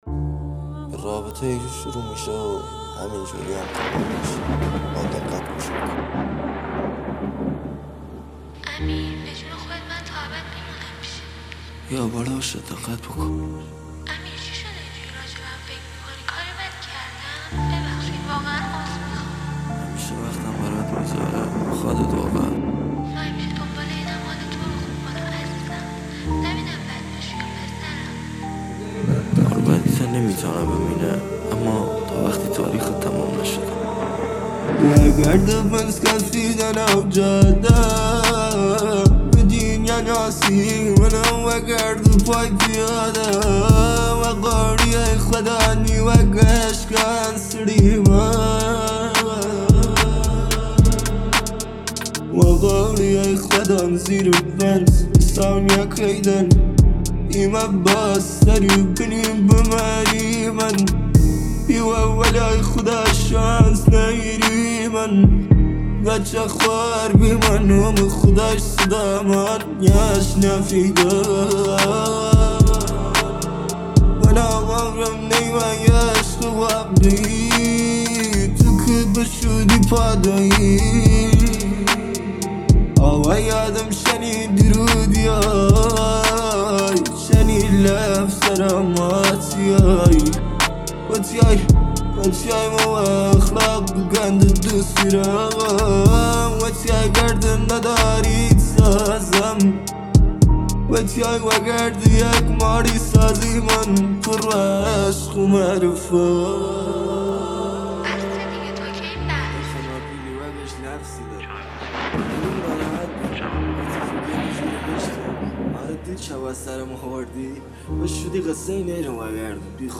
غمگین
اهنگ کوردی